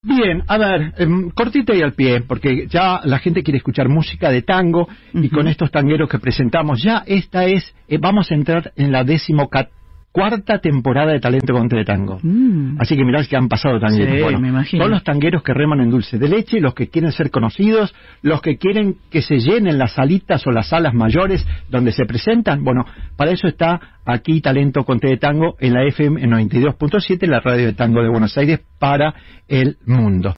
Los sábados de 17 a 19h en la radio de tango de Buenos Aires.